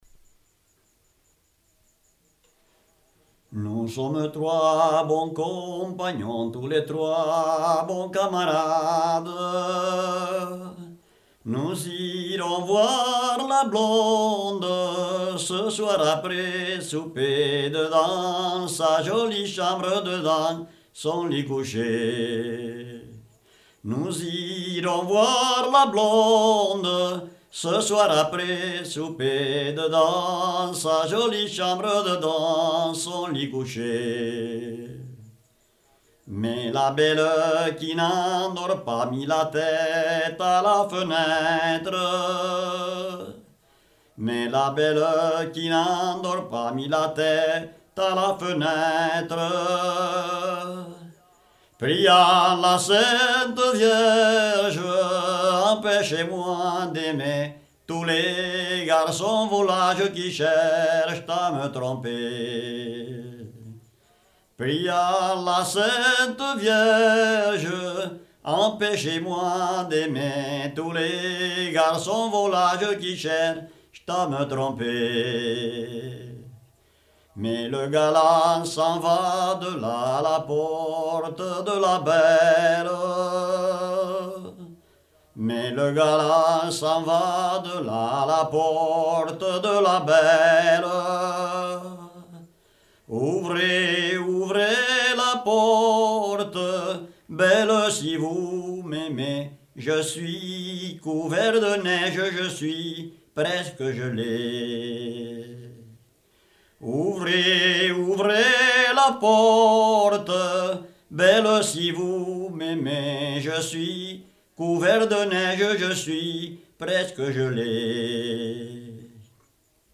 Département : Ariège
Lieu : Ségalas (lieu-dit)
Genre : chant
Effectif : 1
Type de voix : voix d'homme
Production du son : chanté